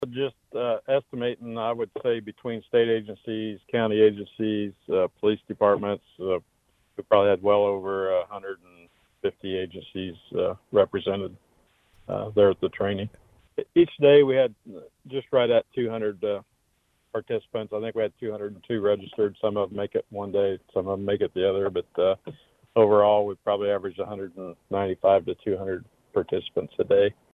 Once again, the Lyon County Sheriff’s Office’s annual statewide spring training seminar was at capacity, according to Lyon County Sheriff Jeff Cope in an interview with KVOE News Friday.